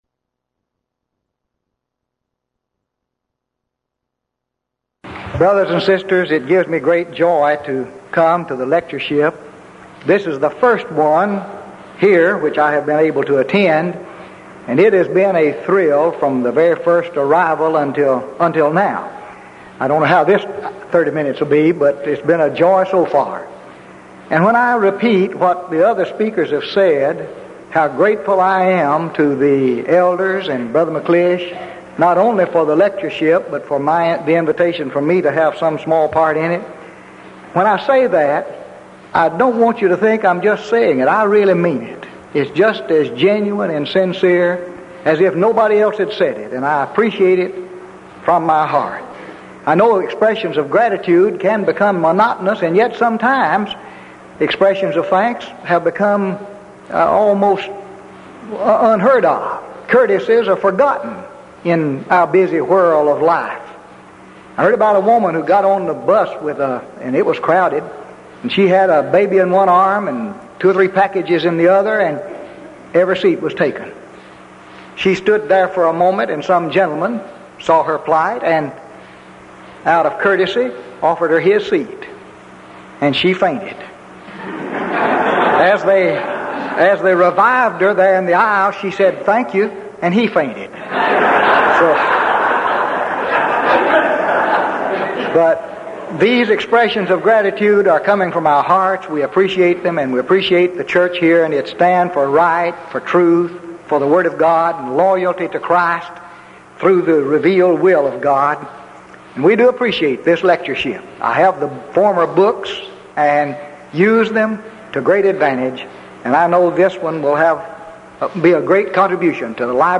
Event: 1985 Denton Lectures Theme/Title: Studies in Acts